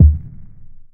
Wierd Kick.wav